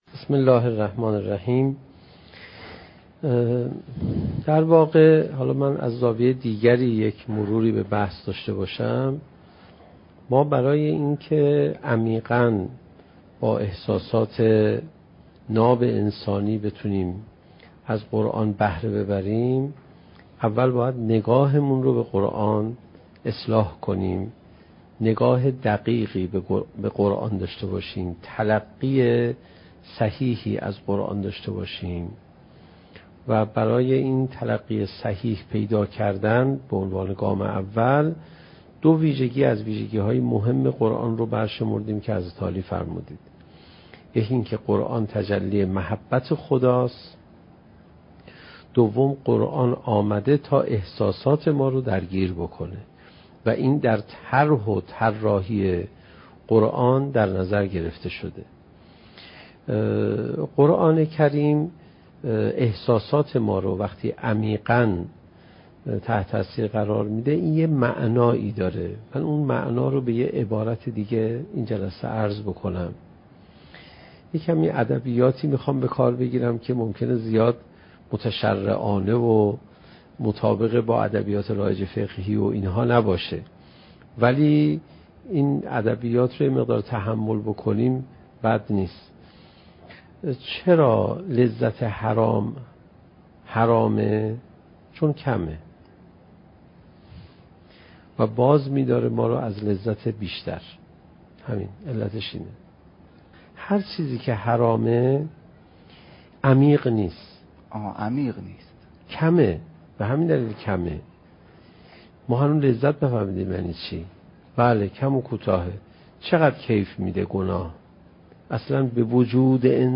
سخنرانی حجت الاسلام علیرضا پناهیان با موضوع "چگونه بهتر قرآن بخوانیم؟"؛ جلسه هشتم: "لذت تلاوت قرآن"